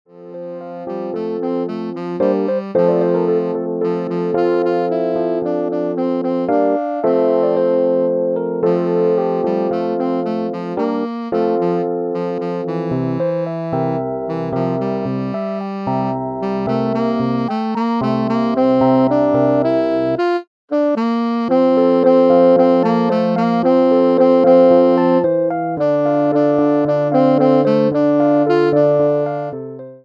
for Tenor Sax & Keyboard